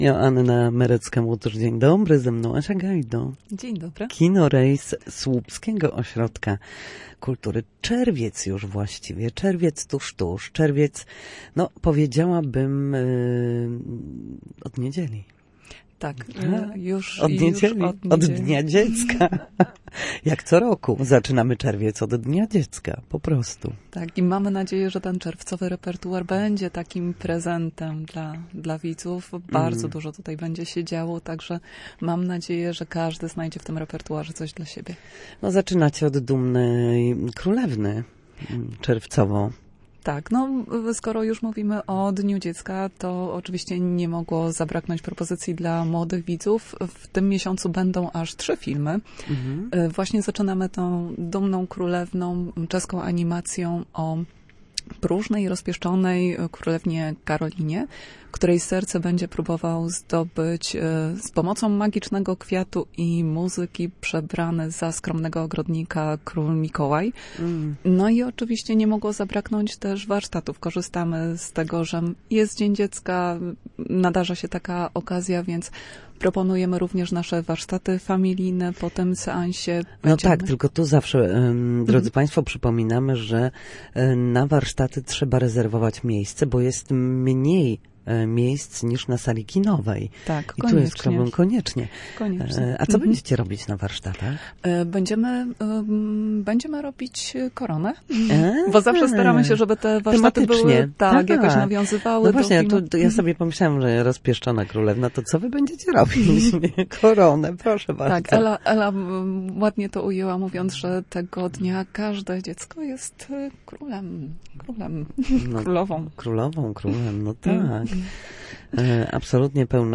Na naszej antenie zapraszała na filmy, które znajdą się w czerwcowym repertuarze.